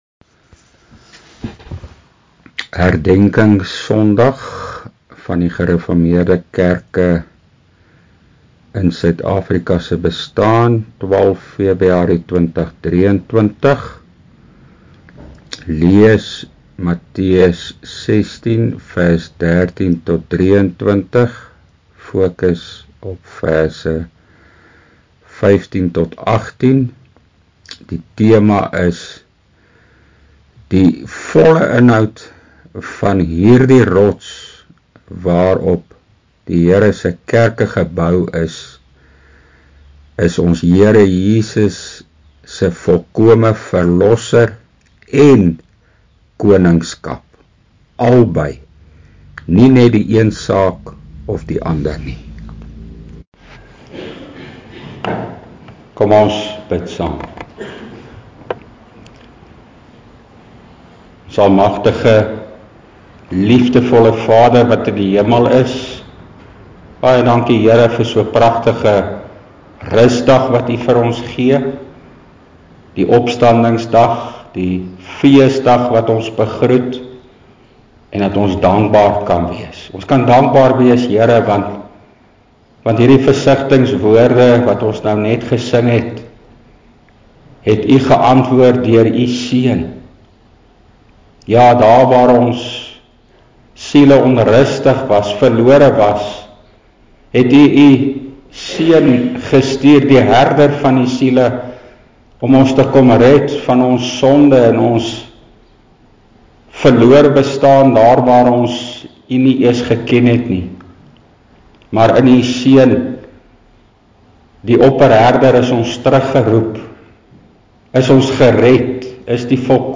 Preekopname: GK Carletonville, 2023-02-12: